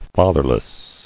[fa·ther·less]